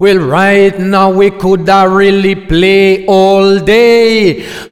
OLDRAGGA3 -L.wav